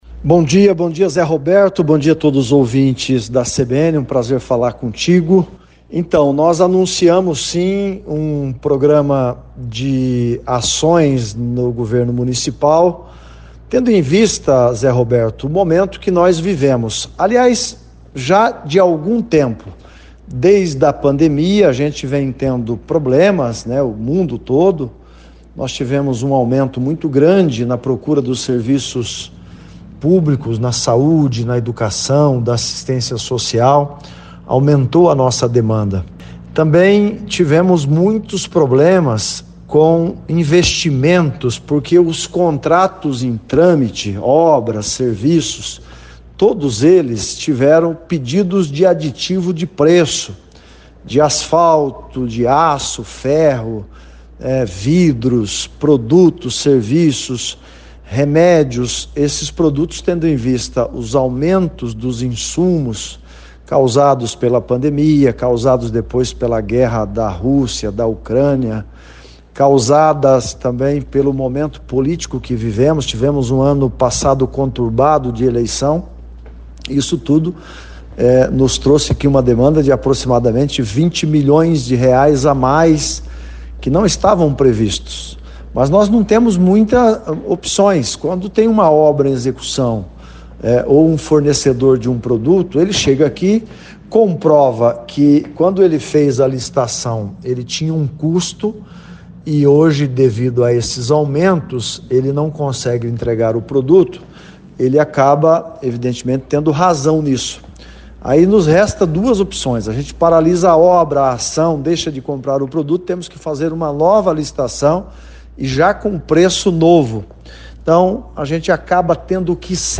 Em entrevista à CBN nesta terça-feira (21) o prefeito de Cascavel, Leonaldo Paranhos, anunciou corte de despesas, entre outras medidas, diante da crise e dos impactos causados pela queda na arrecadação.